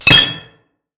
M1加兰德步枪
Tag: 枪支 战斗 步枪 M1 武器 加兰德 二战 战斗